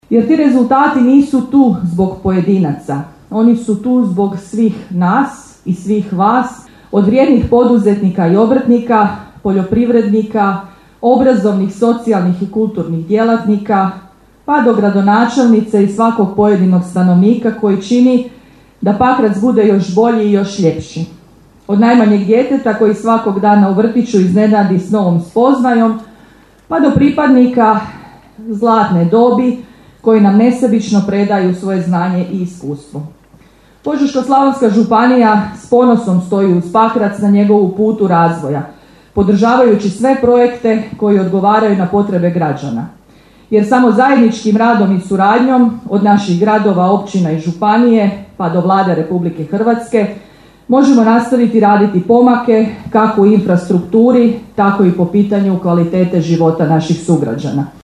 Svečana sjednica Gradskog vijeća Pakraca upriličena je u povodu Dana Grada Pakraca u Hrvatskom domu dr. Franjo Tuđman u Pakracu.
Prisutnima na svečanosti obratila se županica Požeško slavonske županije Antonija Jozić ukazujući na intenzivan posao na izradi projektno tehničke dokumentacije za izgradnju zgrade Zavoda za hitnu medicinu u Pakracu, najavivši skoro raspisivanja Javnog poziva za Mjere pomoći Požeško-slavonske županije u rješavanju stambenog pitanja mladim obiteljima.